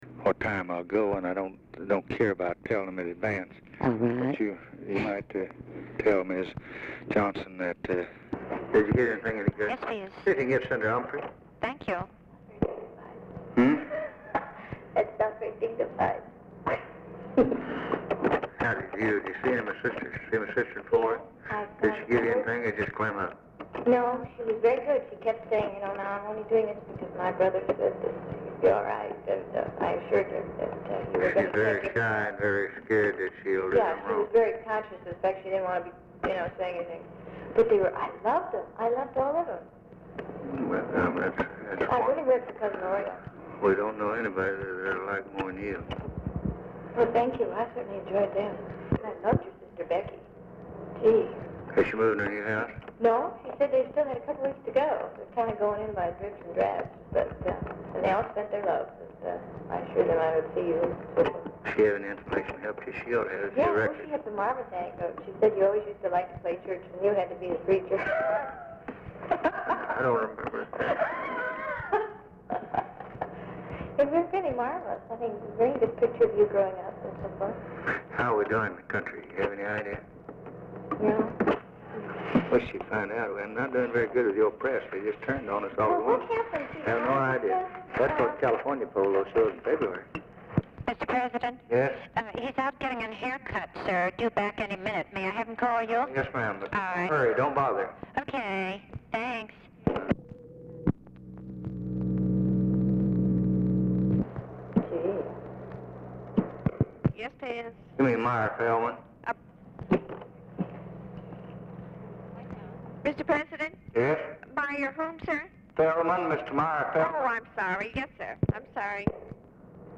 Telephone conversation # 2130, sound recording, LBJ and TELEPHONE OPERATOR, 2/19/1964, time unknown | Discover LBJ
LBJ TALKS TO UNIDENTIFIED FEMALE IN OFFICE; OPERATOR INTERRUPTS WITH INFORMATION ABOUT CALLS
Format Dictation belt
Location Of Speaker 1 Oval Office or unknown location